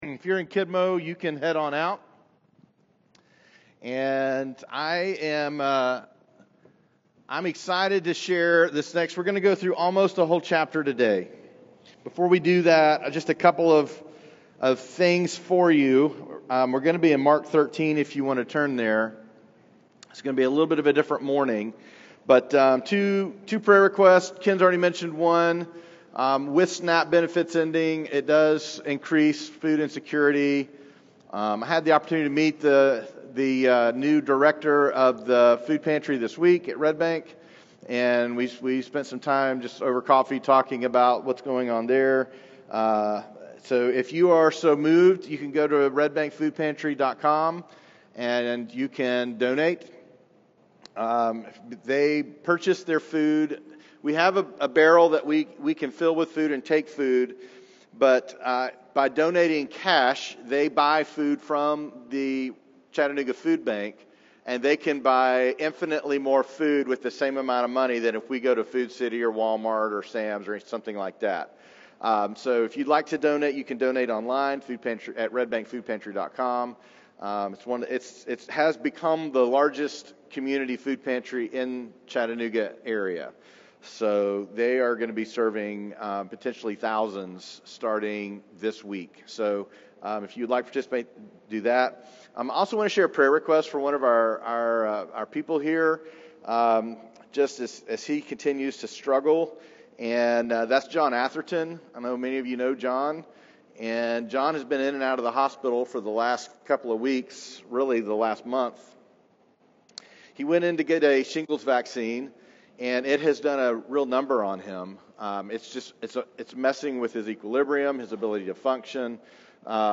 Latest Sermon - Journey Church